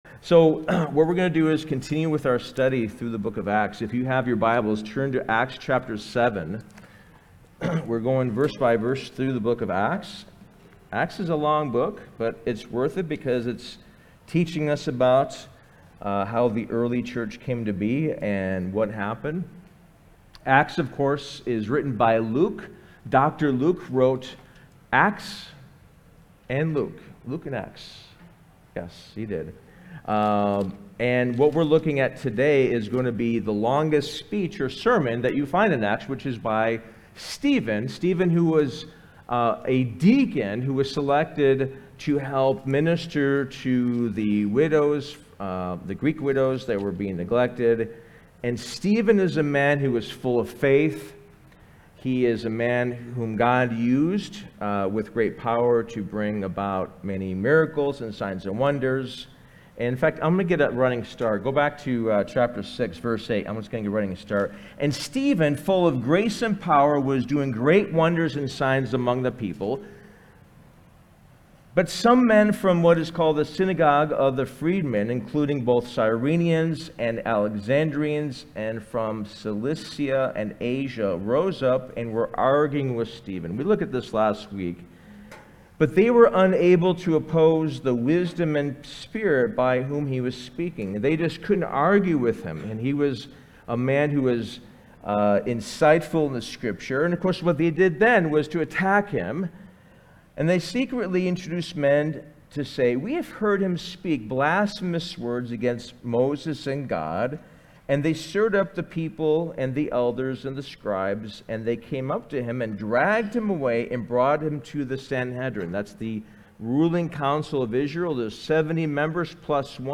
Sermon: Stephen's Defense - Part 1